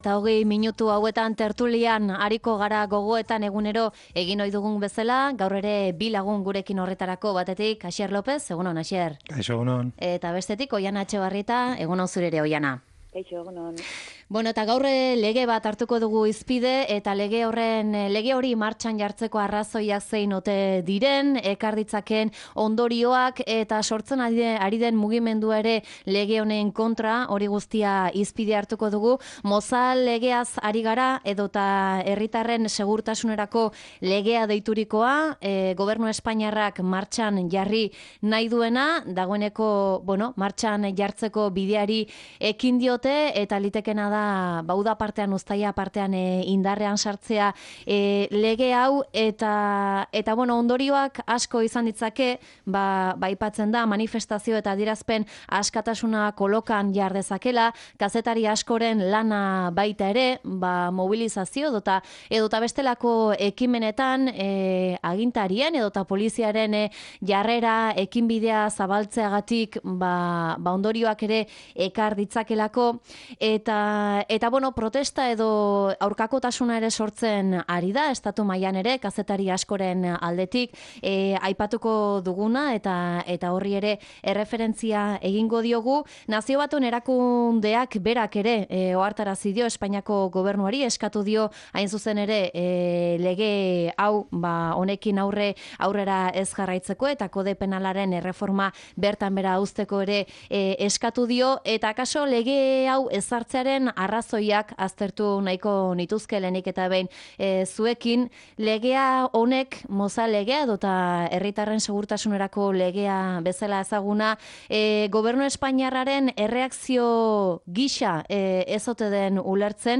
Tertulia: Mozal legea, ekintza eta mezularia jo puntuan